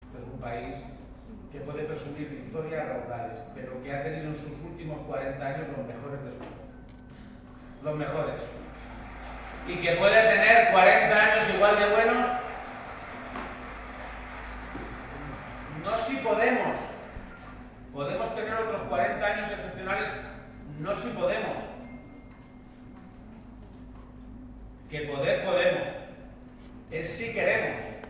Así lo ha subrayado durante su toma de posesión como presidente de la Junta de Comunidades de Castilla-La Mancha por la fórmula de juramento; acto que se ha celebrado este sábado en el Palacio de Fuensalida, sede de la Presidencia autonómica y al que han asistido cerca de 550 personalidades del mundo político, social, cultural, sindical y empresarial, además de familiares y amigos.